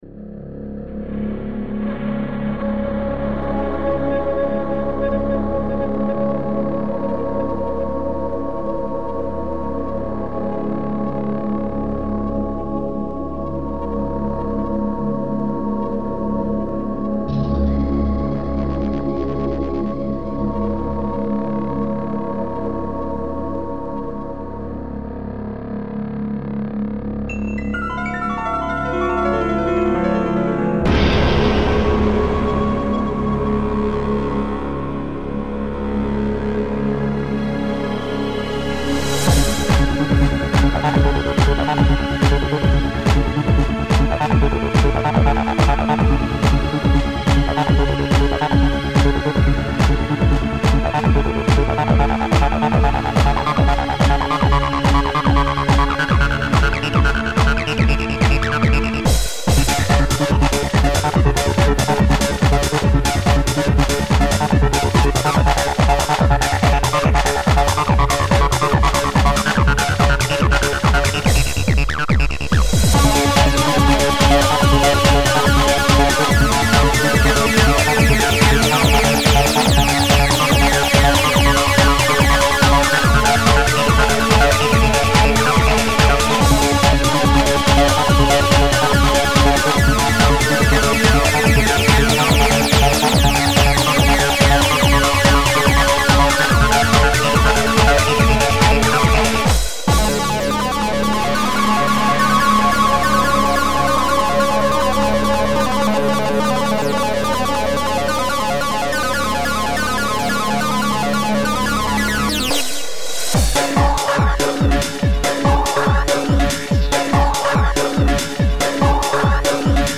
Sound Format: FastTracker v2